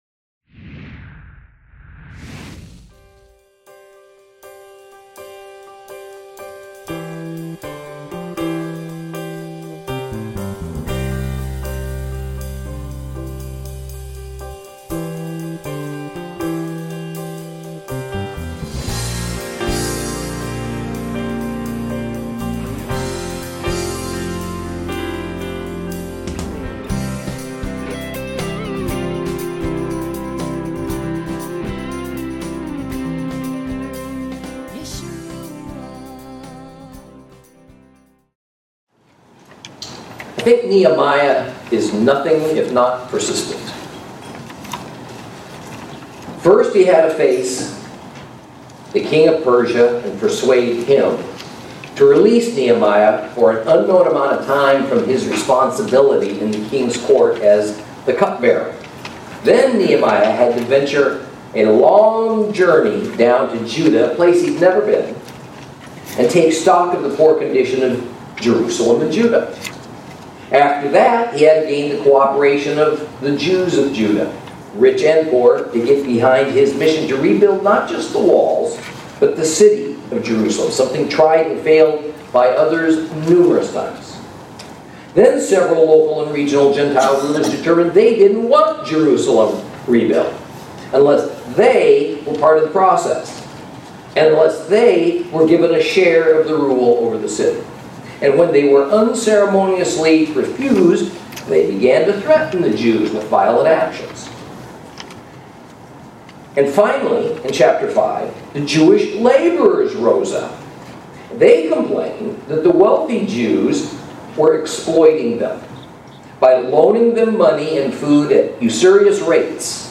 Lesson 8 Ch5 Ch6 - Torah Class